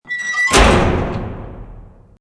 CHQ_door_close.mp3